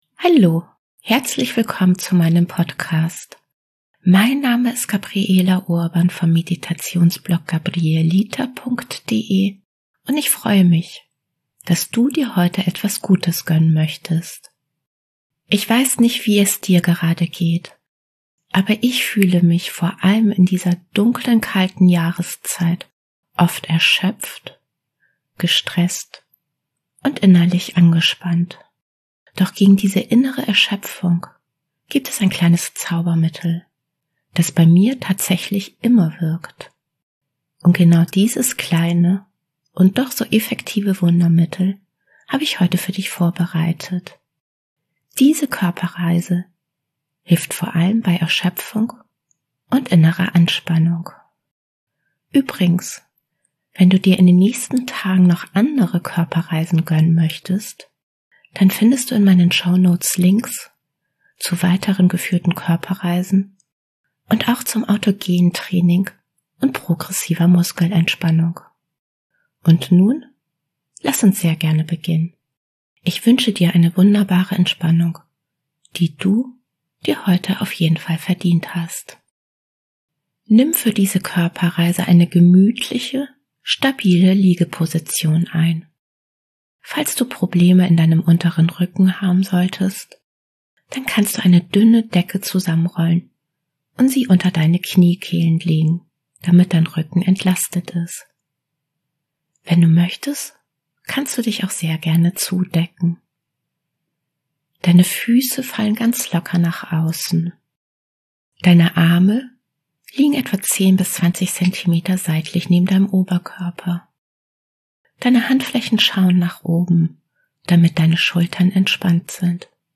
Doch gegen diese innere Erschöpfung gibt es ein kleines Zaubermittel, das bei mir tatsächlich immer wirkt: eine geführte Körperreise bei Erschöpfung.